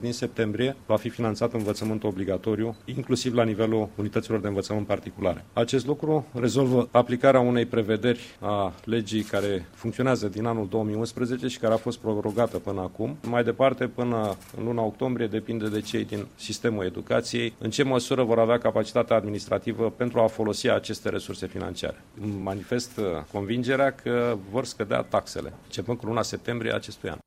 Potrivit ministrului Educaţiei, Sorin Câmpeanu, acest lucru ar putea duce la o scădere a taxelor plătite de părinţi: